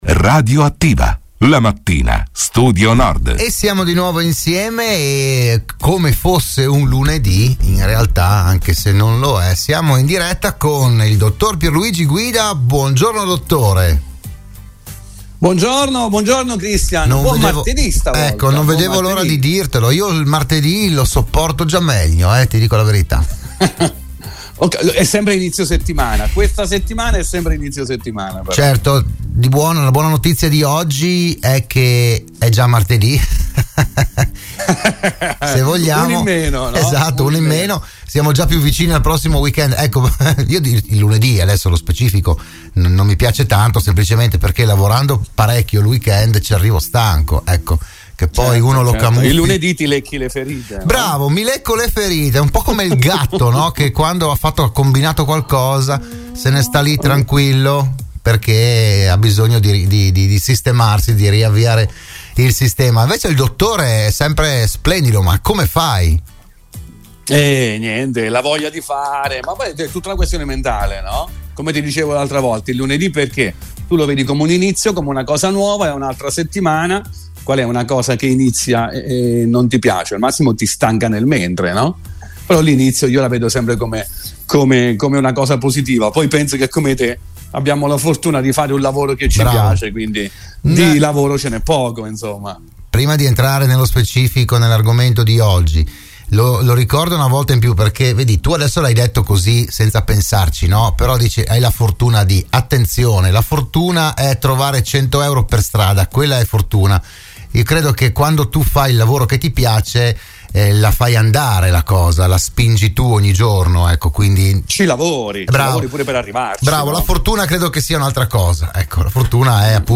Nuova puntata per “Buongiorno Dottore”, il programma di prevenzione e medicina di Radio Studio Nord.